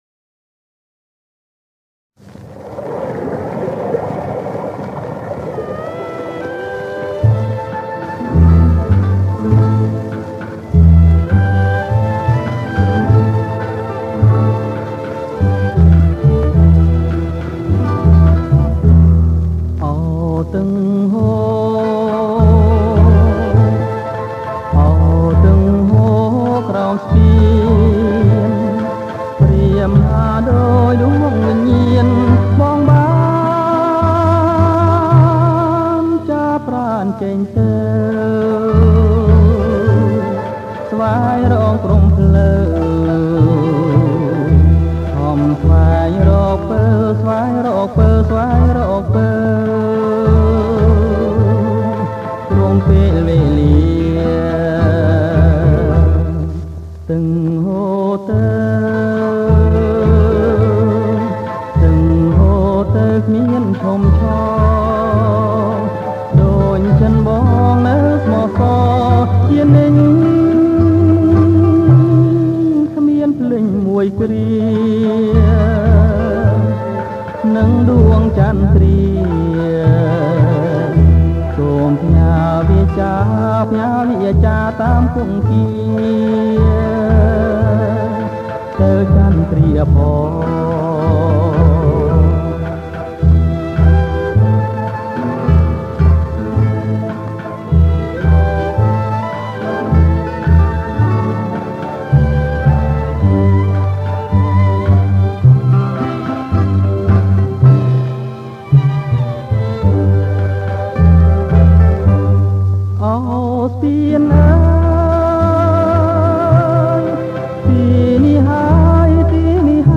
• ប្រគំជាចង្វាក់ Bolero Twist